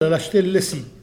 Elle provient de Petosse.
Catégorie Locution ( parler, expression, langue,... )